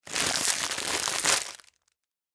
Звуки целлофана
Тихий звук раскрытия пакета